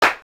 LM-1_CLAP_1_TL.wav